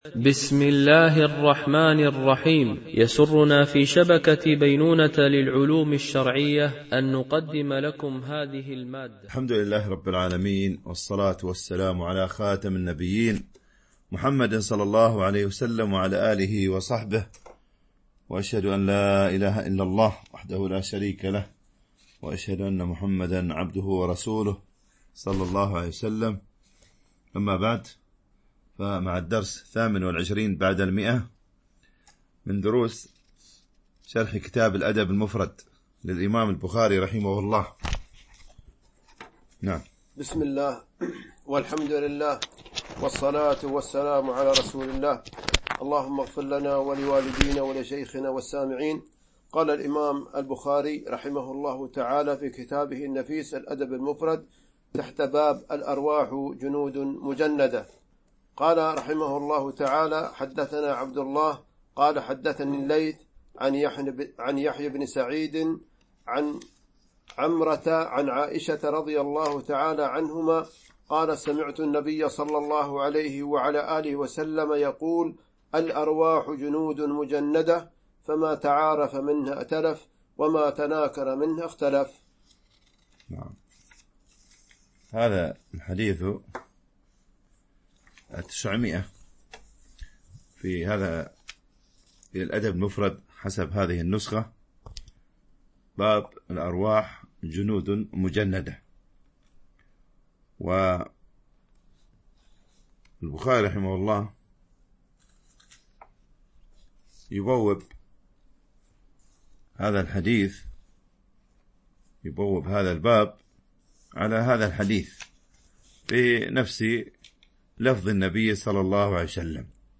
شرح الأدب المفرد للبخاري ـ الدرس 128 ( الحديث 900 - 904 )
MP3 Mono 22kHz 32Kbps (CBR)